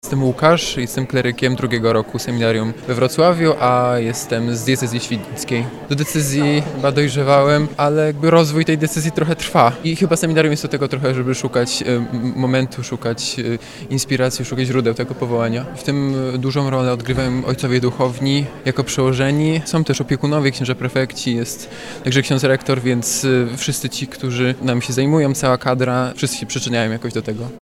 Pytamy też kleryków, co pomaga im w studiach i realizowaniu powołania do kapłaństwa.
04_kleryk.mp3